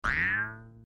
KART_tossBanana.mp3